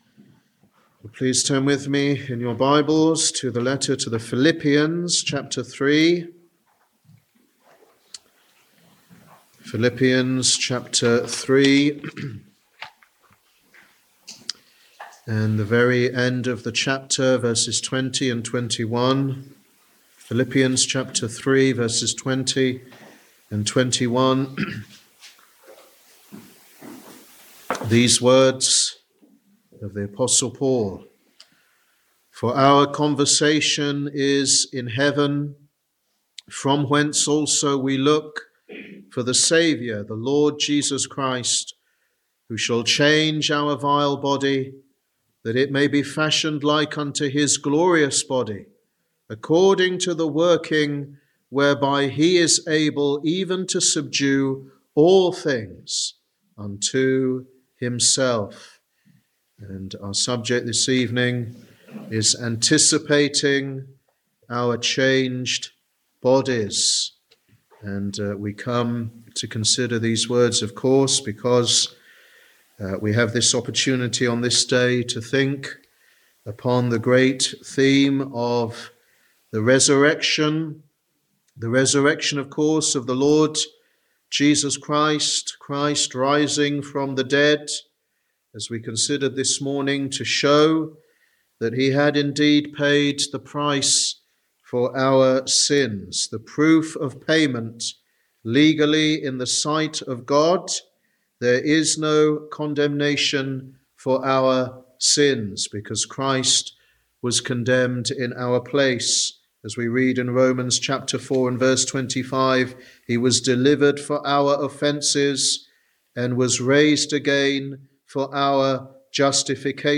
Sermon: Anticipating our Changed Bodies
Easter Evening Service 2025 Philippians 3.20-21